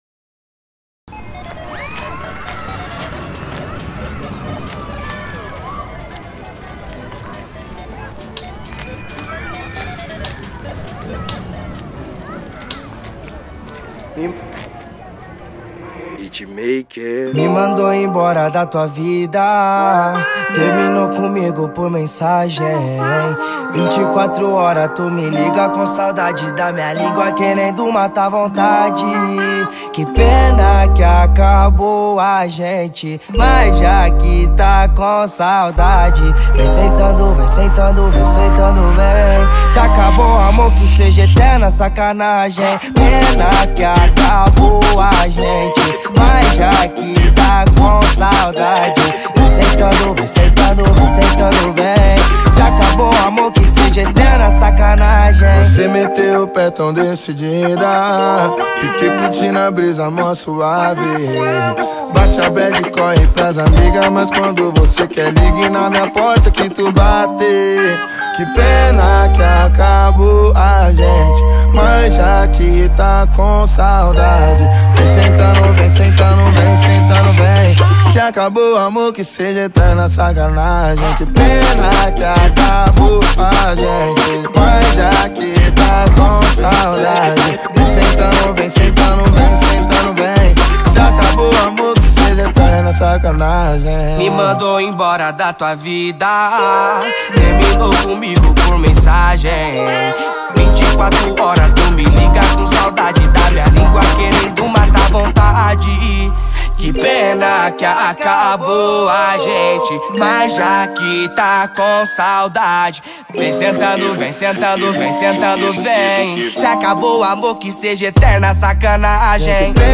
Baixar Funk